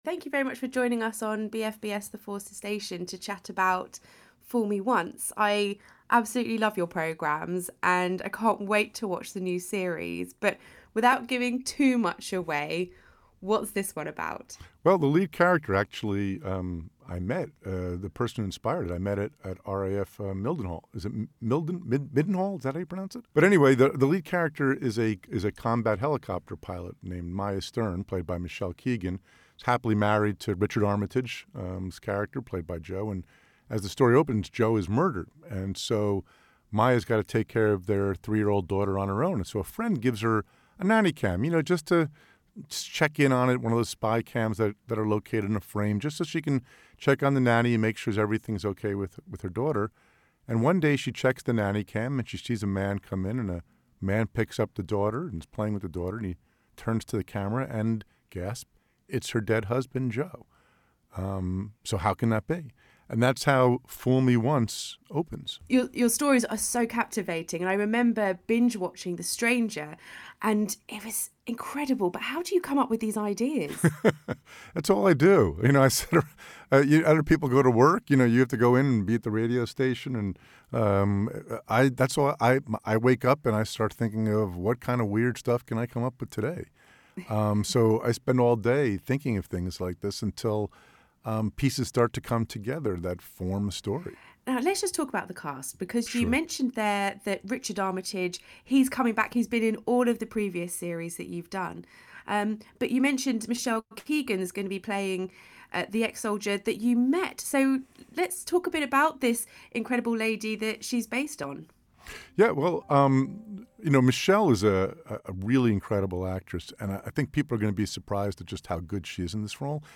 Author Harlan Coben talks about Fool Me Once